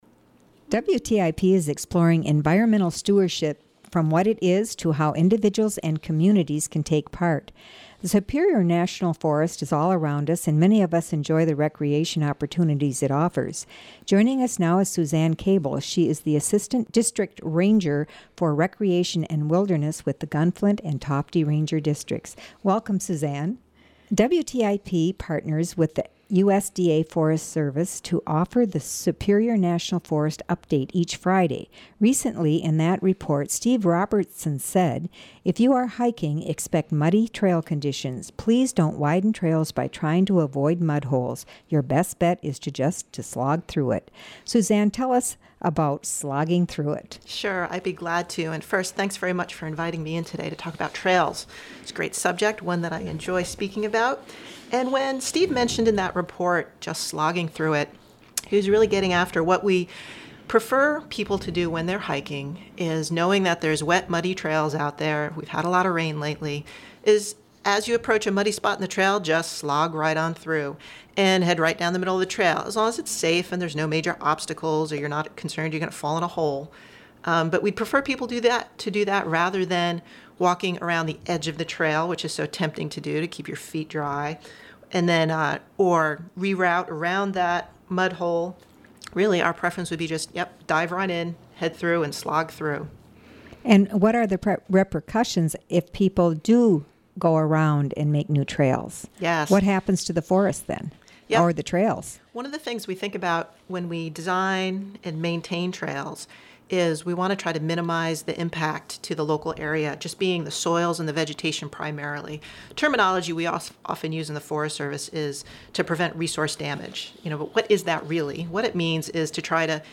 Recommendation: slog through the mud | WTIP North Shore Community Radio, Cook County, Minnesota